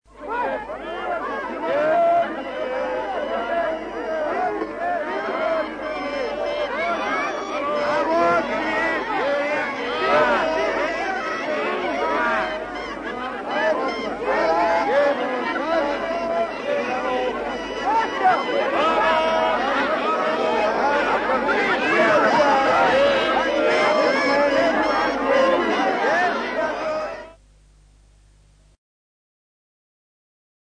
На этой странице собраны разнообразные звуки рынка: гул толпы, крики продавцов, стук товаров, смех покупателей.
Гомін звуків на ринку в СРСР